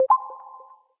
click-short-confirm.ogg